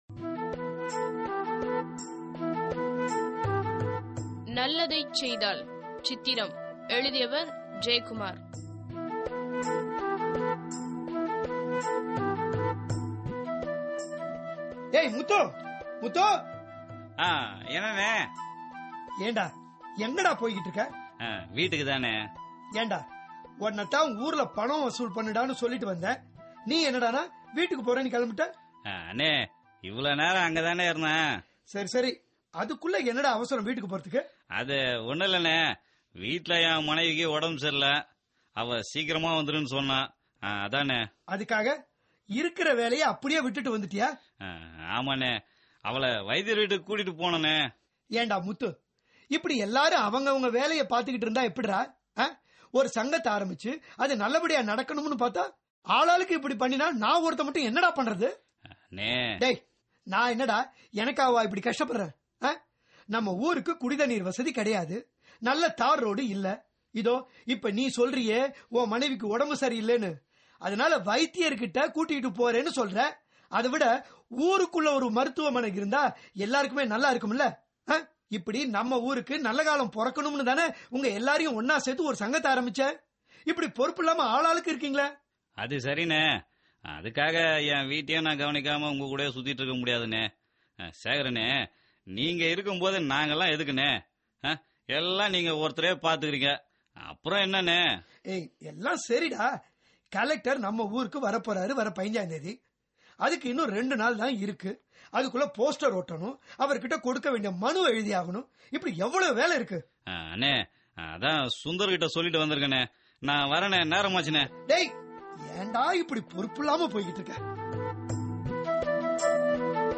Directory Listing of mp3files/Tamil/Dramas/Social Drama/ (Tamil Archive)